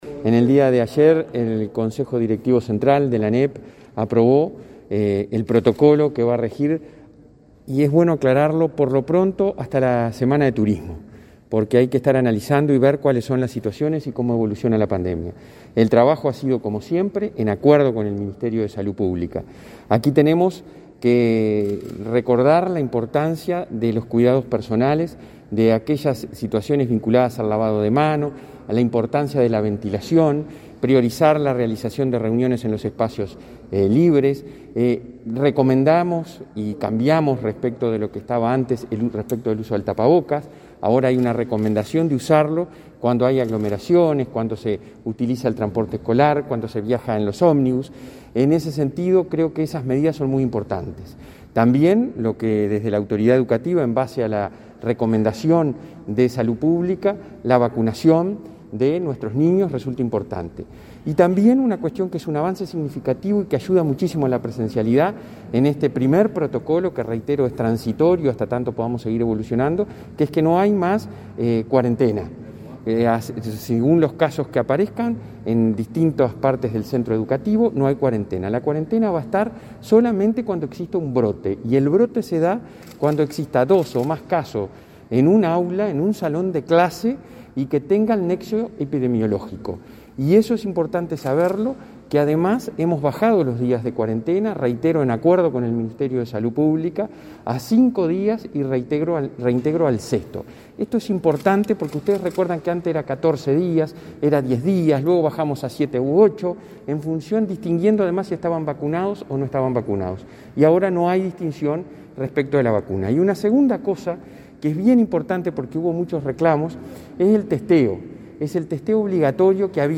Declaraciones del presidente de ANEP, Robert Silva
El presidente del Consejo Directivo Central (Codicen) de la Administración Nacional de Educación Pública (ANEP), Robert Silva, informó a la prensa